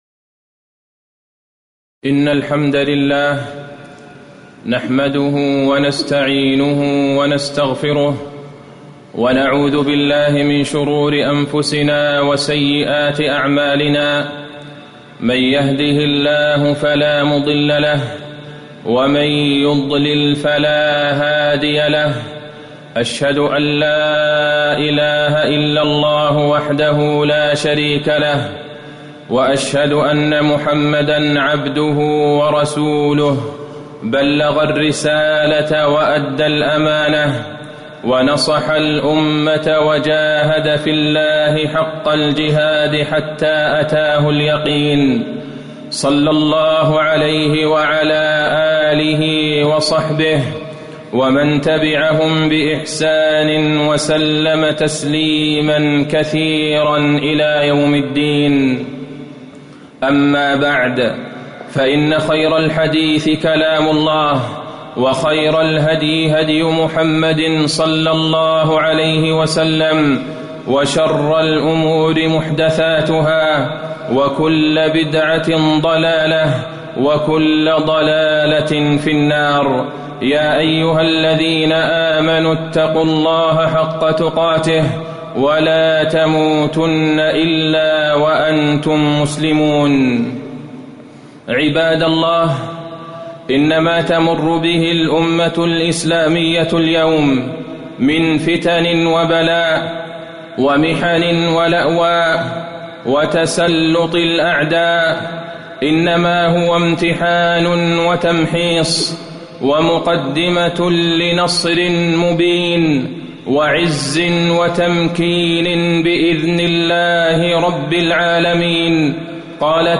خطبة العزة والنصر والتمكين للإسلام؛ بلال رضي الله عنه نموذجا وفيها: حال الأمة اليوم، وأول من أظهر الإسلام، وقصة بلال رضي الله عنه
تاريخ النشر ٧ صفر ١٤٣٩ المكان: المسجد النبوي الشيخ: فضيلة الشيخ د. عبدالله بن عبدالرحمن البعيجان فضيلة الشيخ د. عبدالله بن عبدالرحمن البعيجان العزة والنصر والتمكين للإسلام .